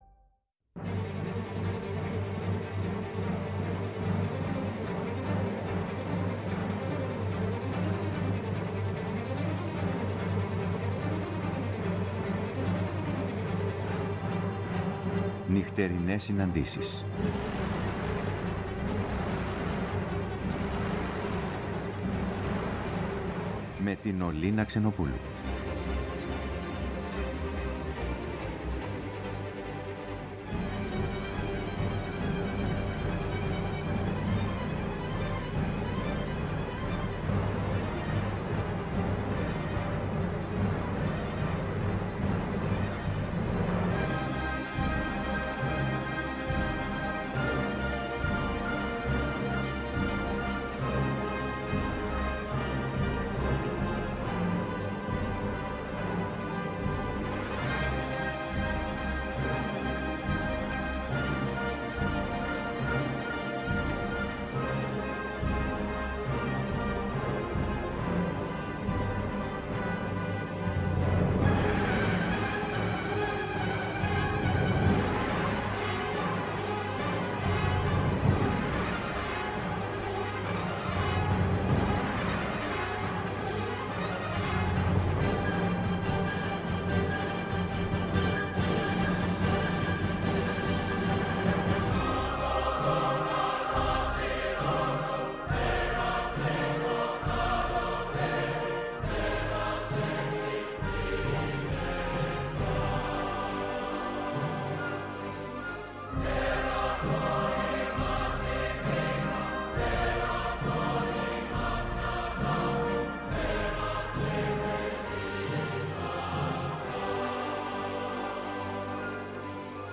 Η μουσική συνοδεύει, εκφράζοντας το «ανείπωτο».